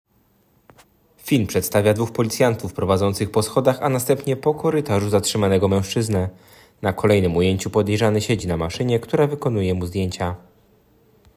Opis nagrania: Audiodeskrypcja filmu schron